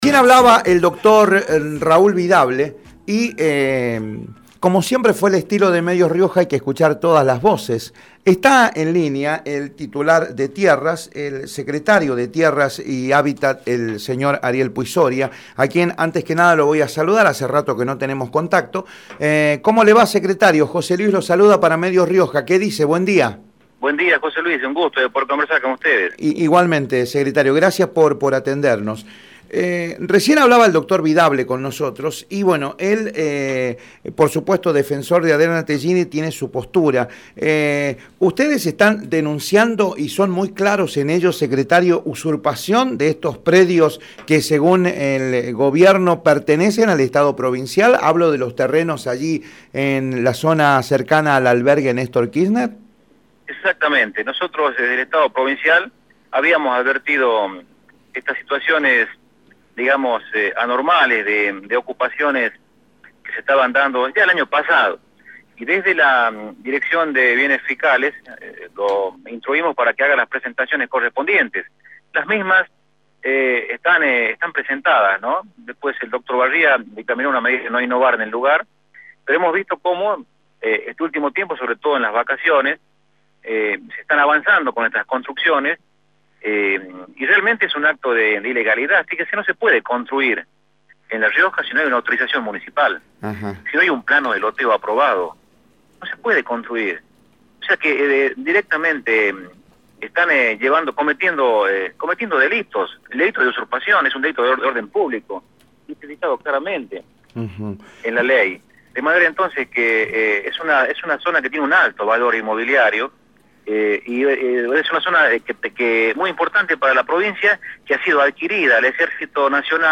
Ariel Puy Soria, titular de Tierras, por Radio Rioja